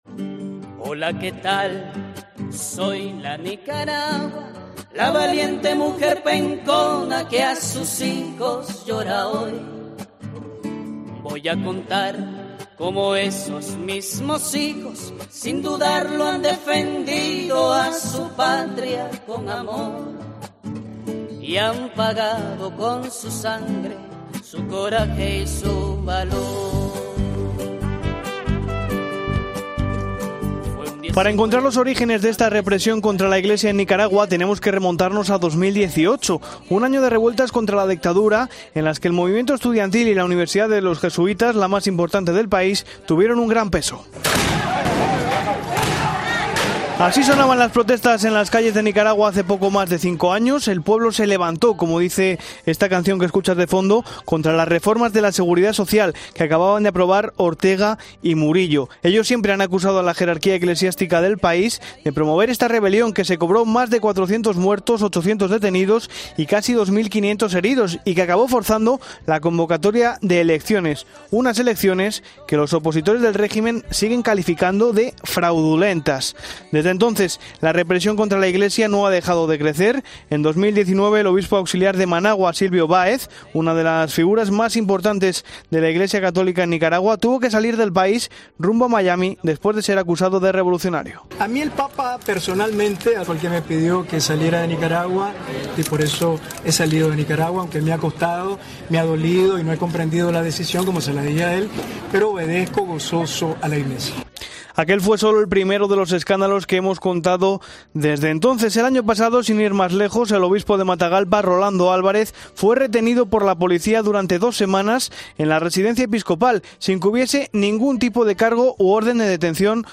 La abogada nicaragüense y defensora de los derechos humanos